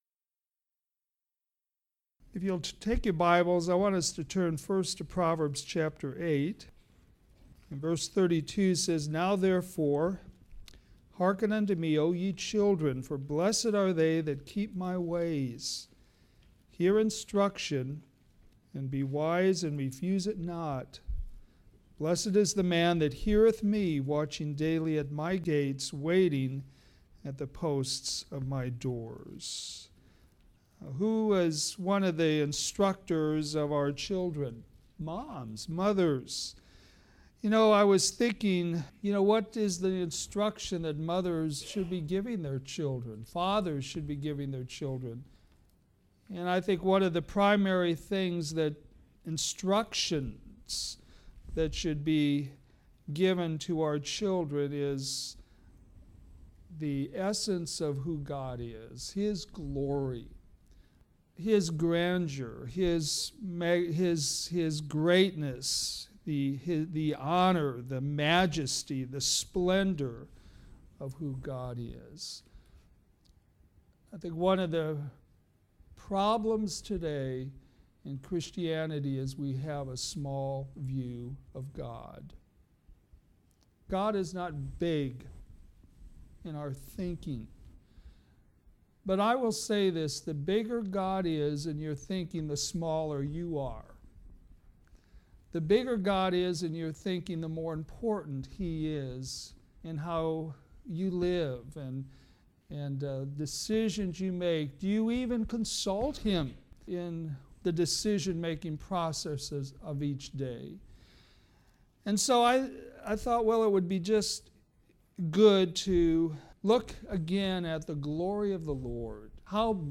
Sermons based on Old Testament Scriptures